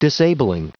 Prononciation du mot disabling en anglais (fichier audio)
Prononciation du mot : disabling